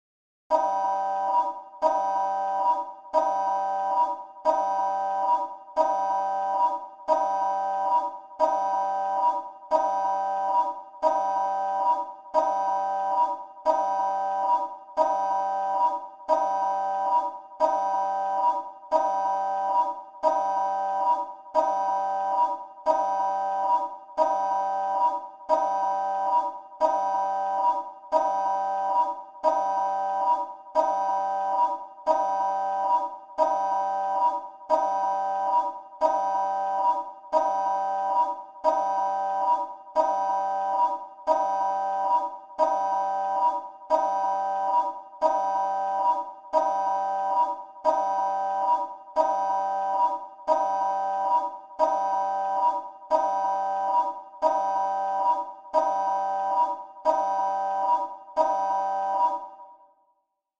На этой странице собраны звуки работающих реакторов — от глухих гулов до мощных импульсов.
Реактор – Сигнал о ядерном распаде